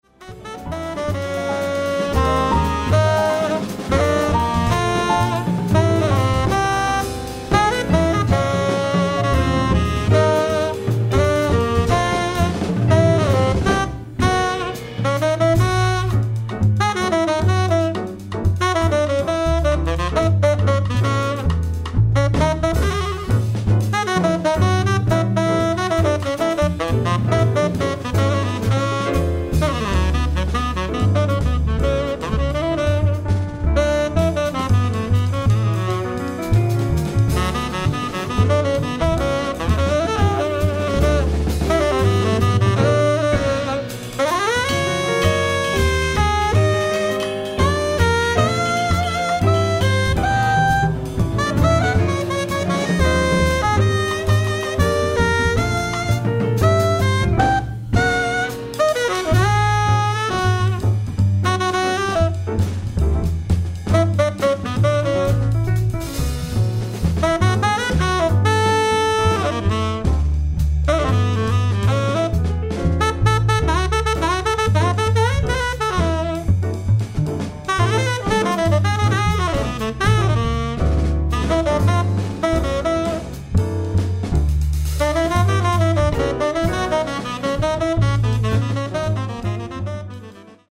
ライブ・アット・ポギー＆ベス・クラブ、ウィーン、オーストリア 03/12/2023
※試聴用に実際より音質を落としています。